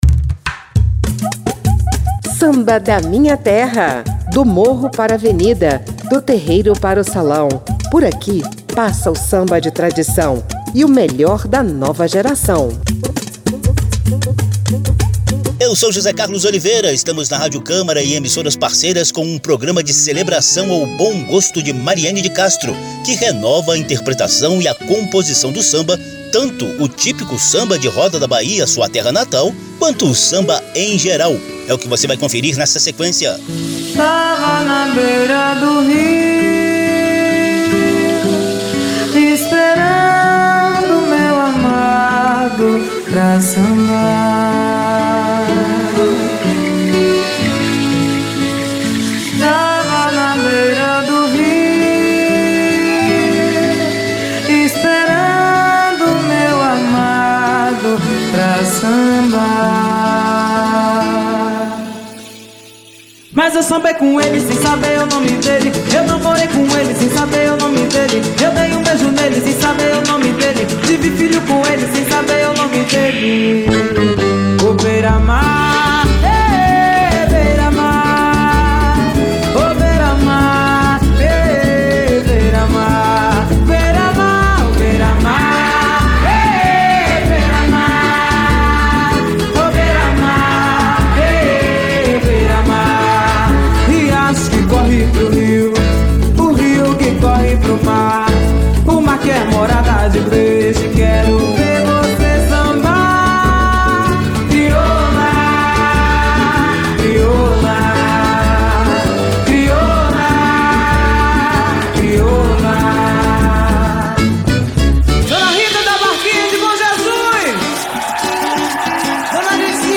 impregnados de africanidade, baianidade e negritude.